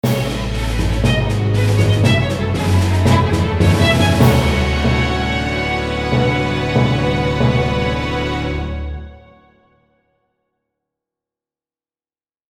Category 😂 Memes